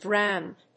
/draʊnd(米国英語)/
アクセントdrówned